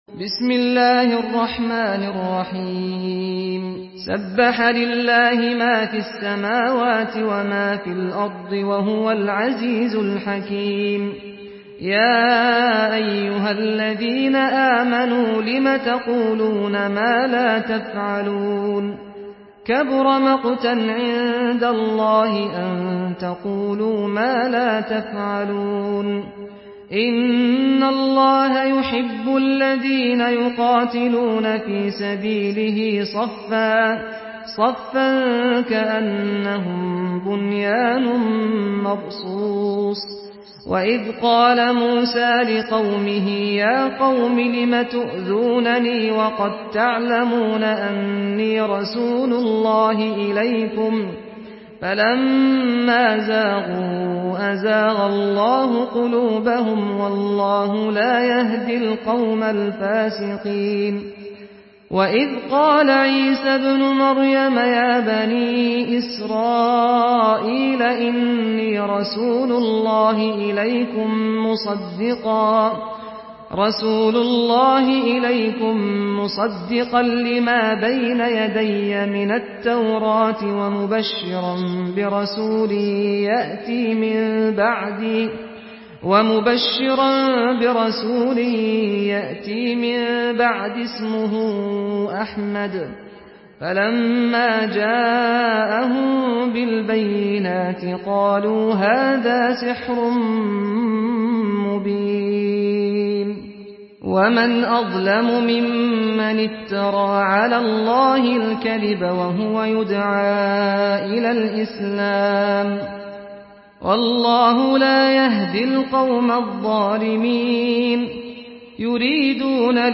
Surah আস-সাফ MP3 in the Voice of Saad Al-Ghamdi in Hafs Narration
Surah আস-সাফ MP3 by Saad Al-Ghamdi in Hafs An Asim narration.
Murattal Hafs An Asim